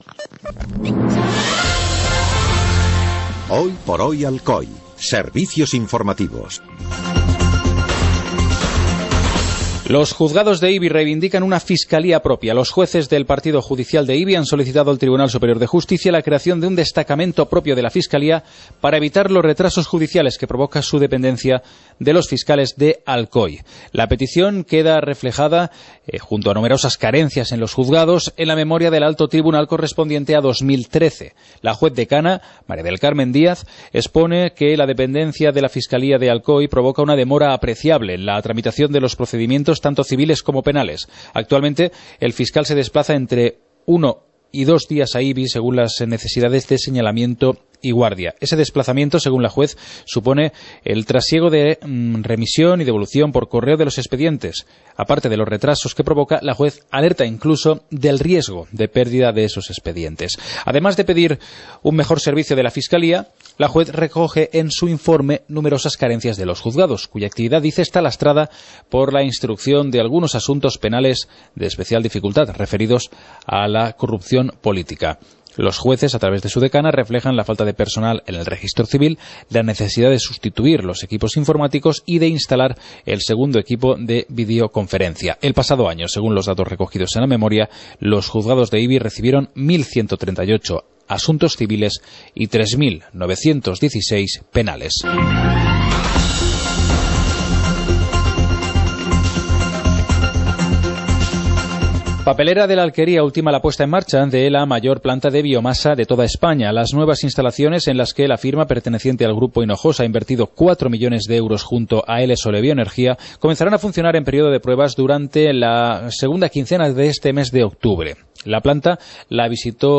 Informativo comarcal - martes, 14 de octubre de 2014